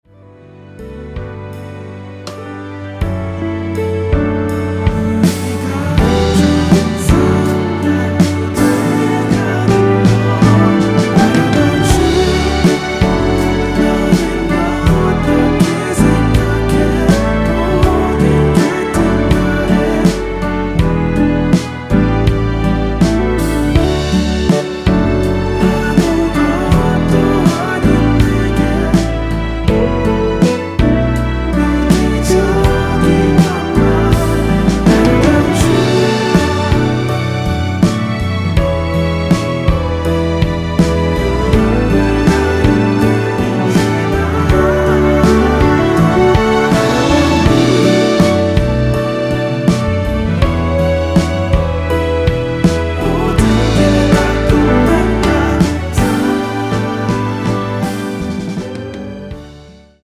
원키 코러스 포함된 MR입니다.(미리듣기 확인)
앞부분30초, 뒷부분30초씩 편집해서 올려 드리고 있습니다.
중간에 음이 끈어지고 다시 나오는 이유는